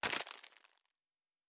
Plastic Foley 06.wav